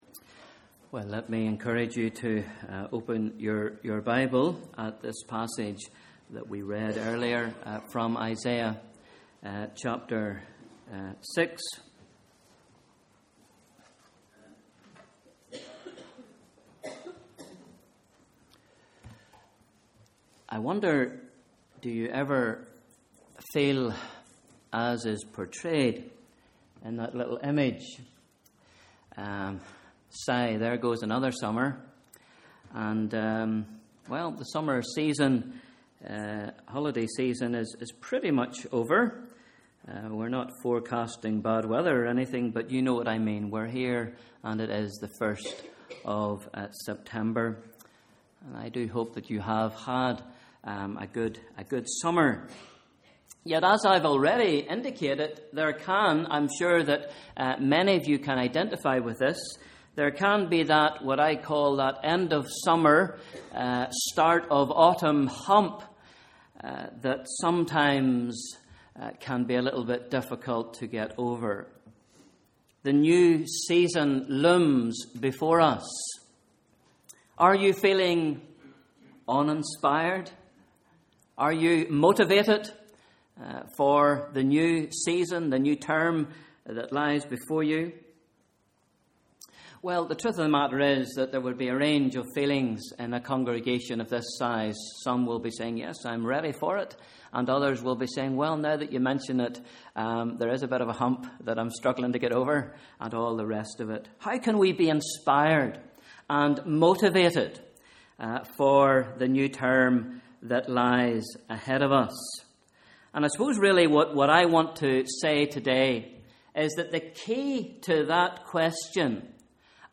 Sunday 1st September: Morning Service / Bible Reading: Isaiah 6